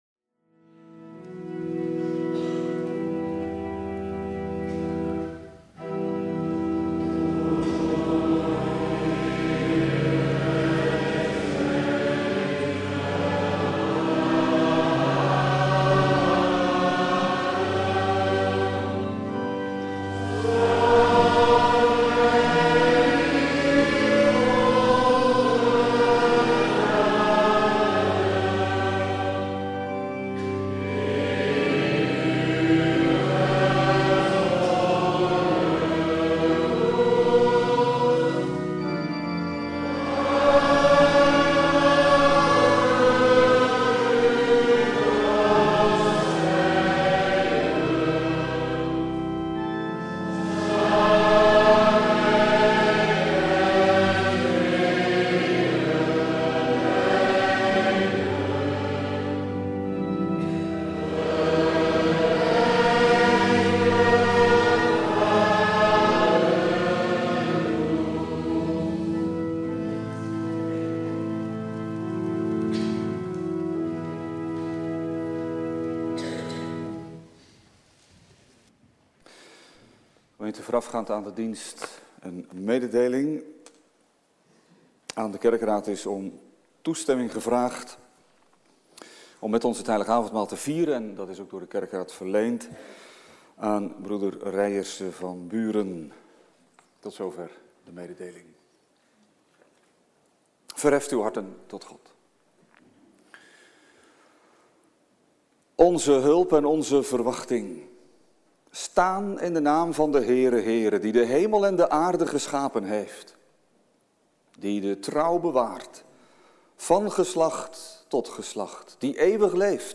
Viering Heilig Avondmaal | CGK Rijnsburg
Eredienst